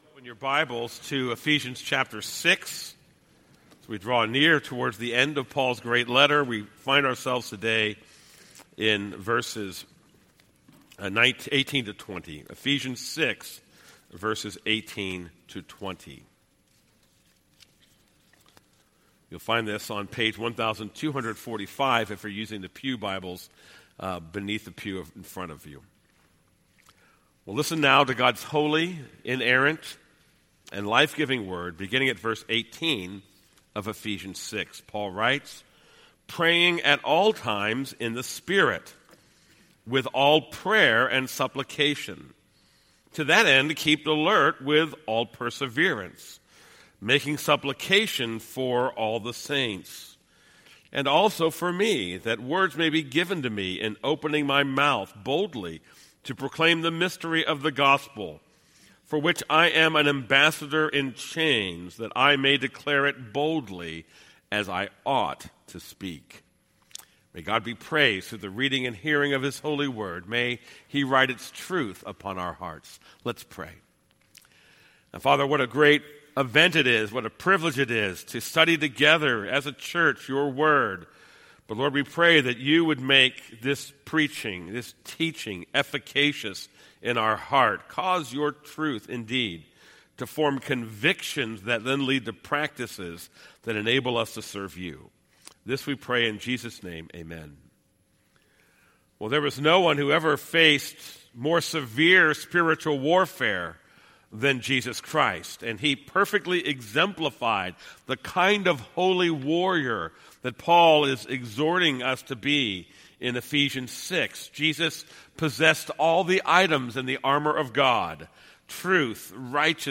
This is a sermon on Ephesians 6:18-20.